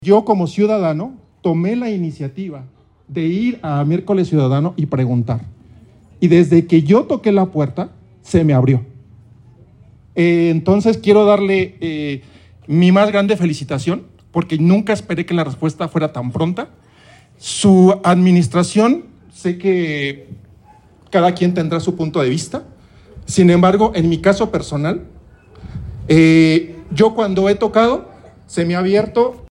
AudioBoletines
vecino de la zona